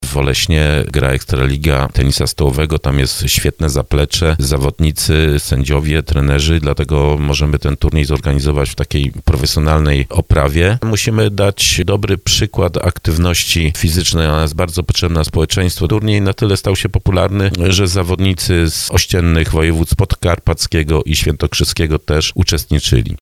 Turniej zostanie rozegrany w hali sportowej przy szkole podstawowej w Oleśnie. Jak mówi pomysłodawca sportowych zmagań samorządowców, starosta dąbrowski Lesław Wieczorek, lokalizacja została wybrana nieprzypadkowo.